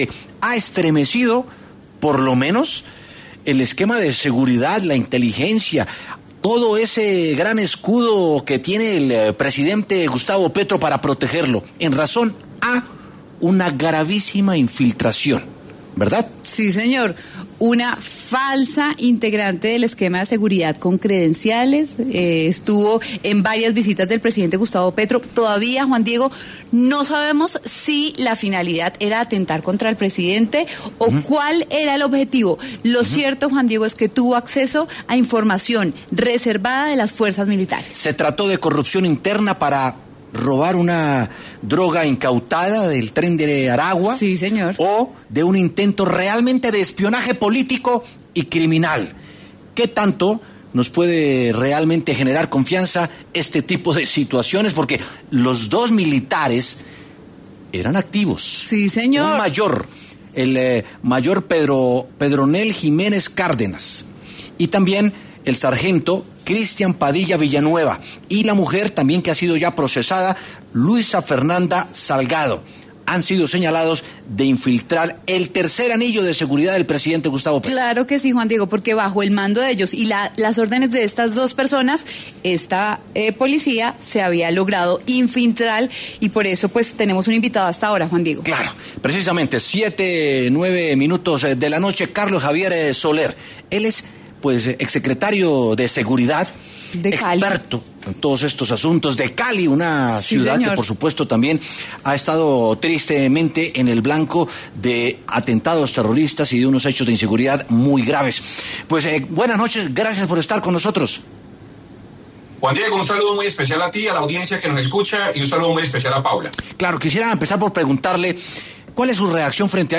El exsecretario de Seguridad de Cali, Carlos Javier Soler, conversó con W Sin Carreta sobre la reciente infiltración al esquema de seguridad del presidente Petro.